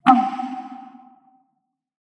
打楽器のひとつ、つづみ（鼓）を叩いた時の音。